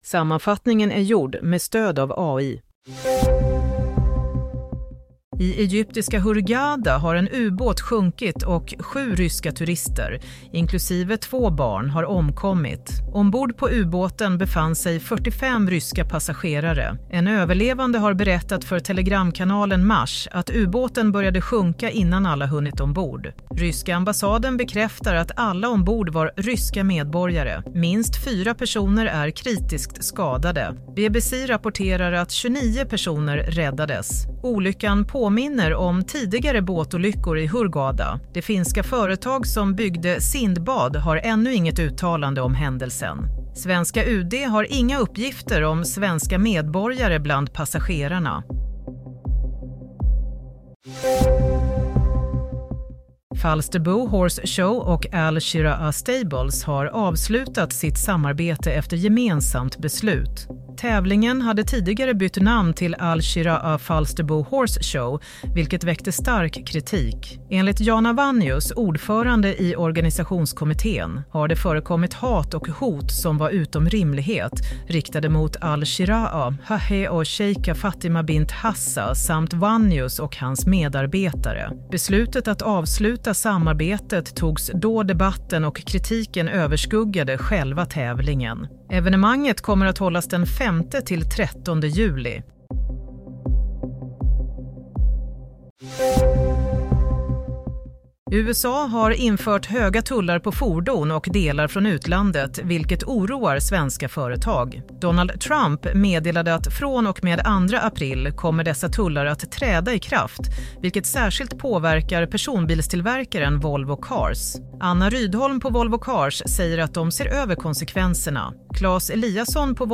Nyhetssammanfattning - 27 mars 16:00